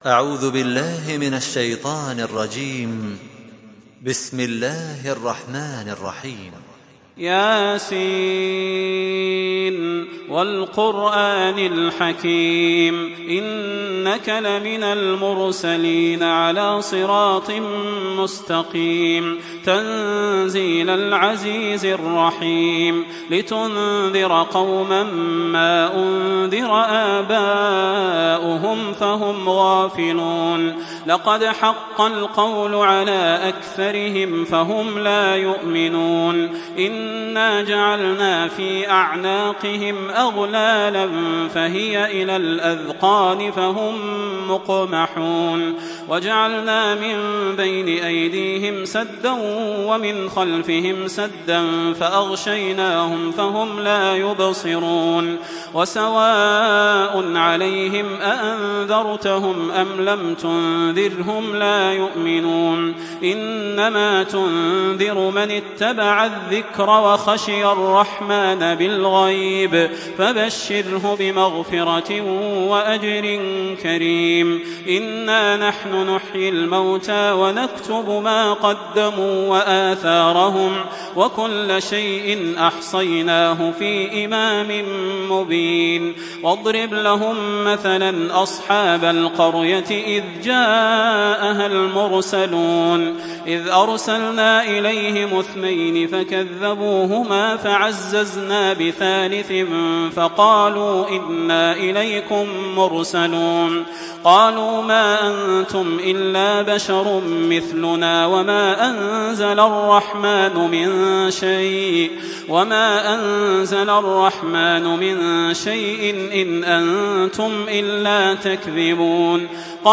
تحميل سورة يس بصوت تراويح الحرم المكي 1427
مرتل